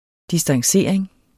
Udtale [ disdɑŋˈseɐ̯ˀeŋ ]